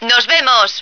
flak_m/sounds/female2/est/F2seeya.ogg at 9e43bf8b8b72e4d1bdb10b178f911b1f5fce2398